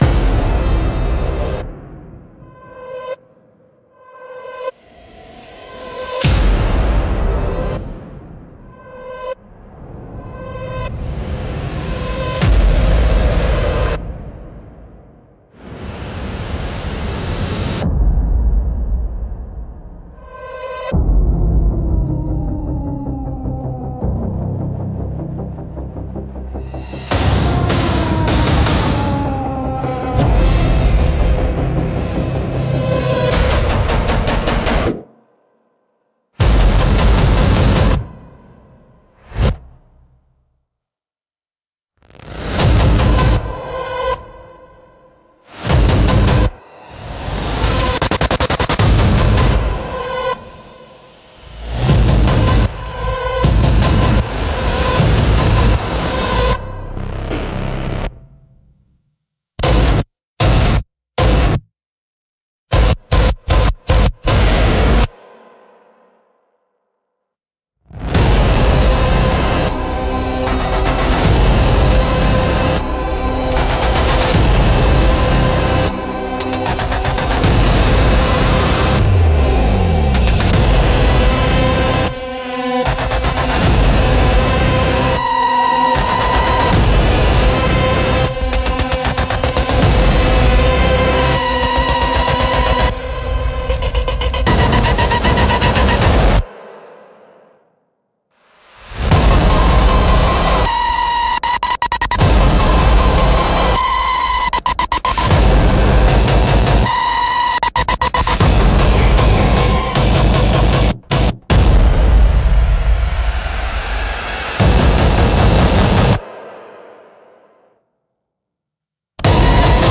Cinematic Trailer Music / Horror
Mood: Epic, Horror, Scary, Build-up